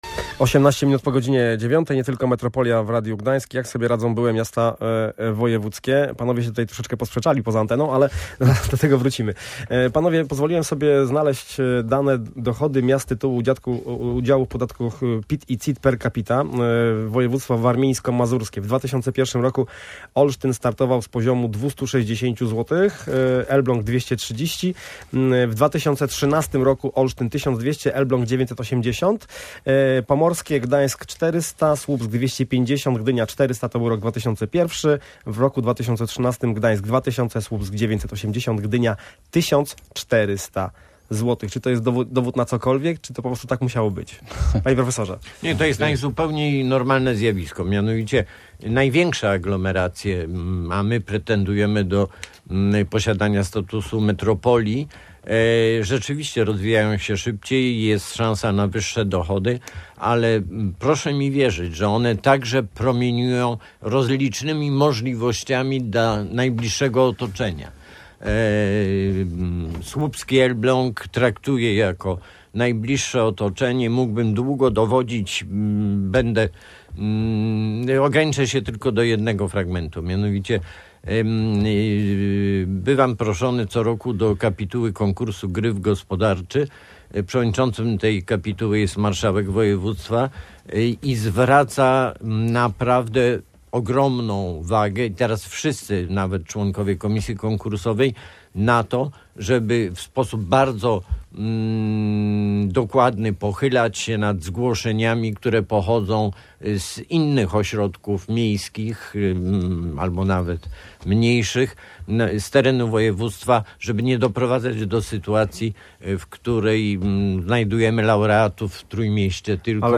Na te pytania odpowiadali goście audycji Nie Tylko Metropolia.